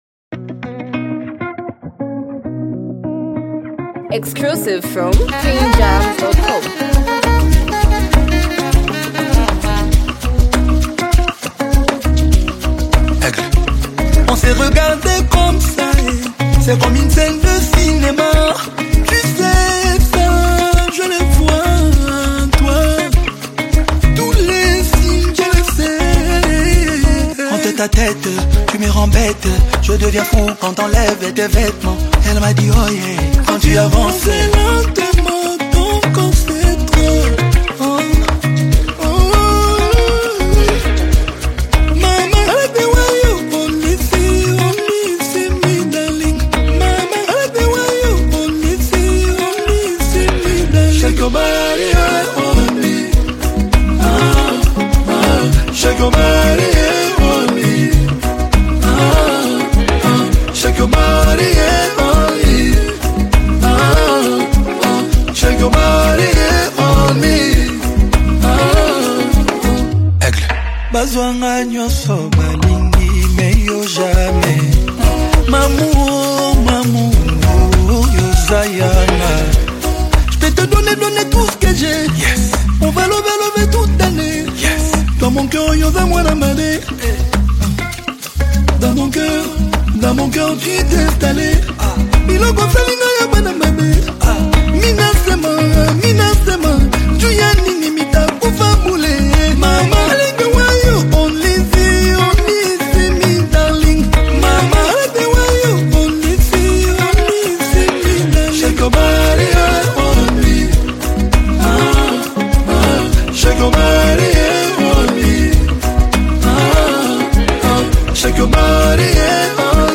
a smooth and captivating single
blends Afropop with the signature Congolese rumba flavor
A romantic, smooth, and well-executed masterpiece